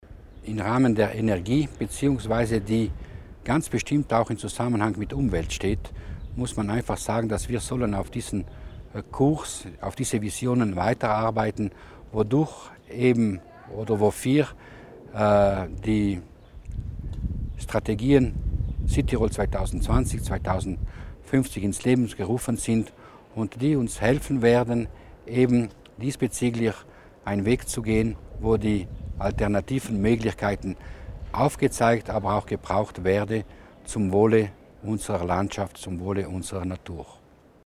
Dass die Energiepolitik weit mehr sei als die SEL, dass an günstigeren Strompreisen gearbeitet werde und dass die Pflicht eines KlimaHaus-Standards A für Neubauten noch einmal überdacht werden müsse, hat Landesrat Florian Mussner in seinem Mediengespräch zum Legislaturende klar gemacht. Mussner hat auch gefordert, dass neben Lang- und Plattkofel auch der Sellastock ins Unesco-Welterbegebiet aufgenommen werden müsse.